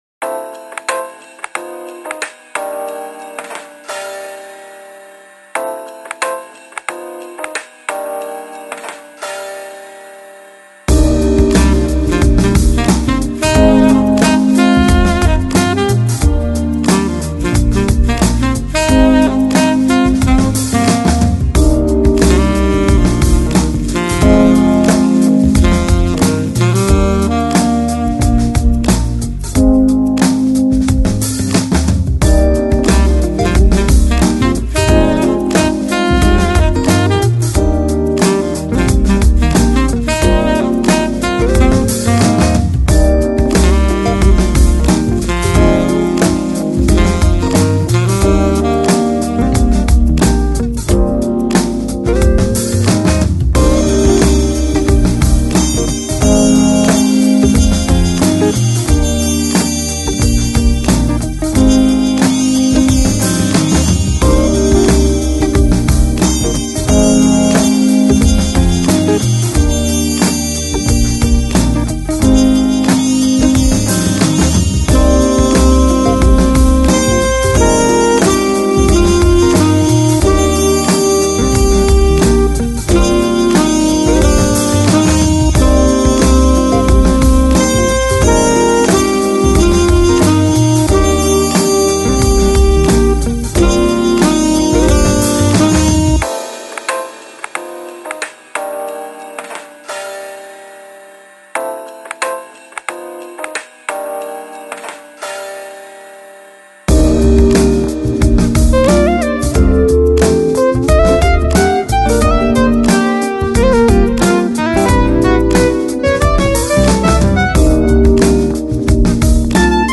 Жанр: Lounge, Chill Out, Smooth Jazz